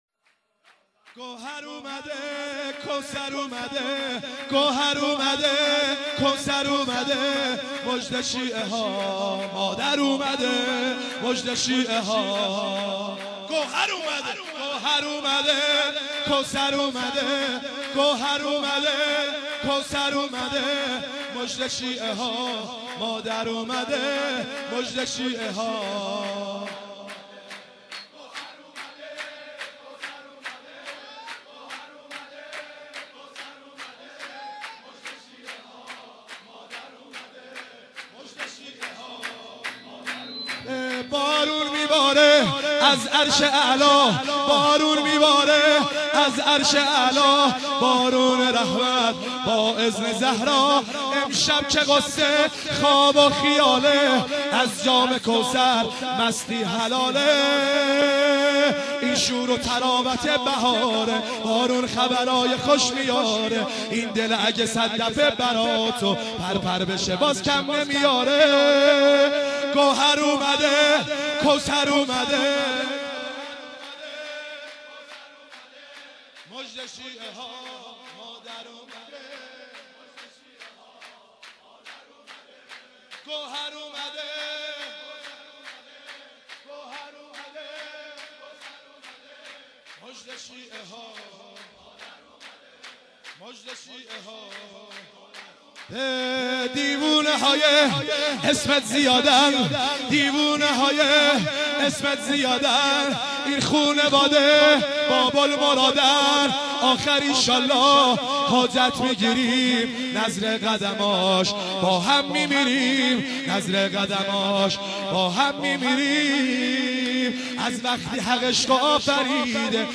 مولودی خوانی